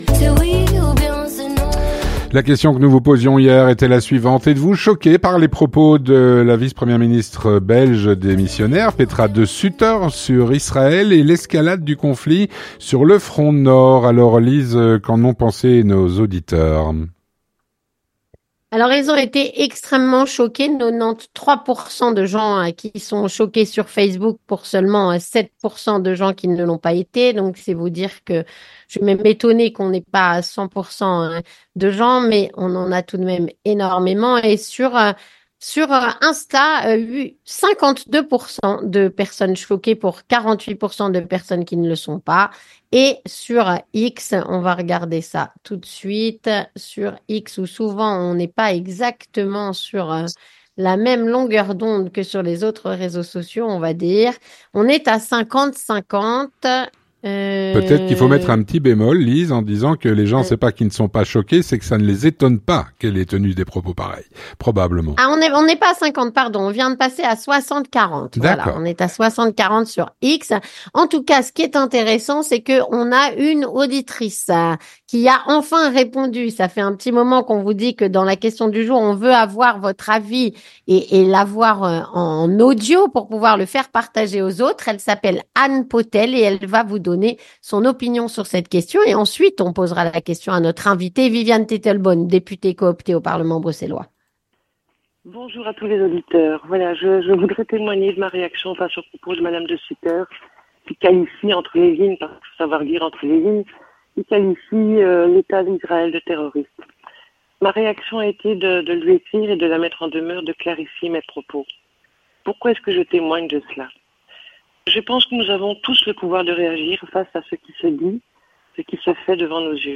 Viviane Teitelbaum, sénatrice, répond à "La Question Du Jour"